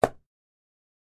sfx-click.ogg